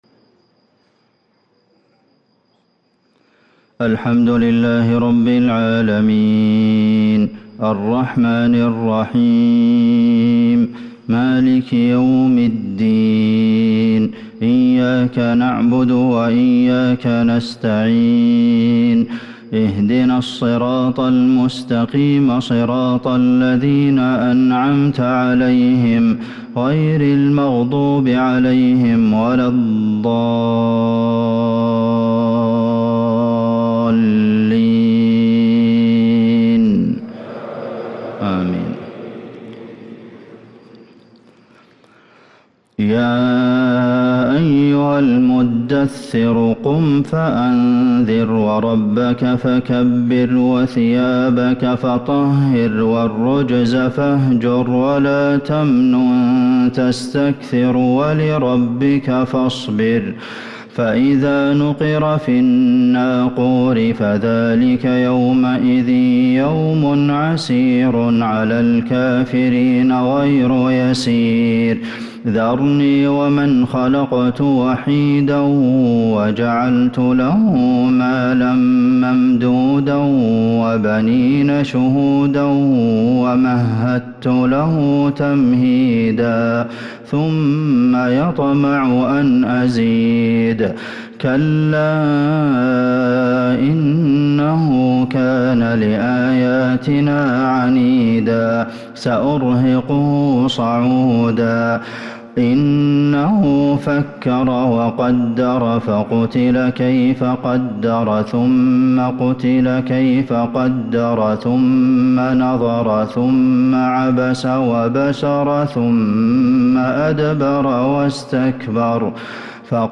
صلاة الفجر للقارئ عبدالمحسن القاسم 26 شوال 1444 هـ
تِلَاوَات الْحَرَمَيْن .